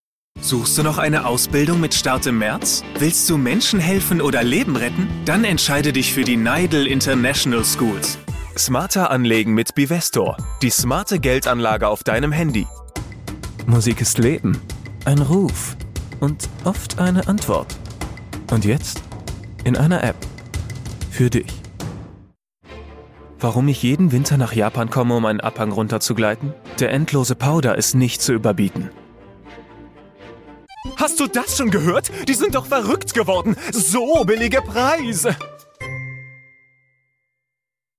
Voice Over Sprecher Off-Sprecher Deutsch Männlich Jung Frisch.
Sprechprobe: Werbung (Muttersprache):